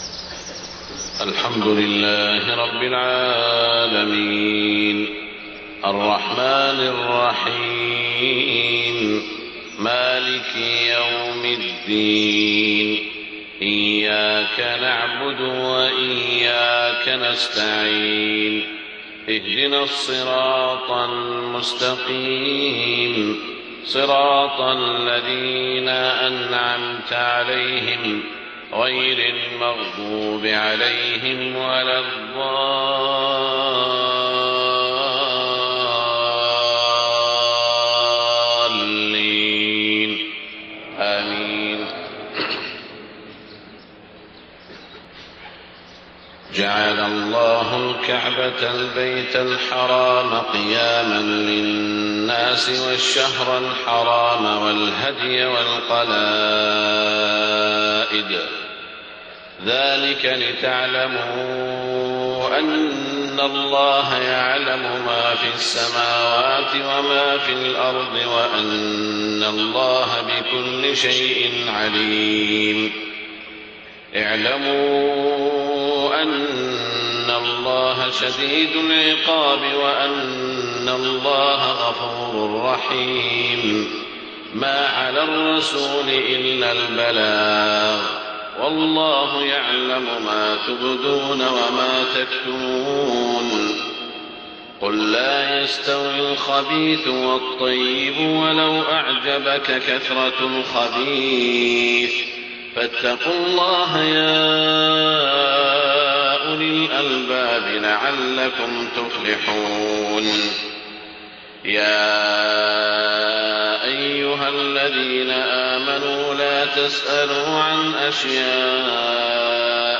صلاة الفجر 30 ذو الحجة 1429هـ من سورة المائدة > 1429 🕋 > الفروض - تلاوات الحرمين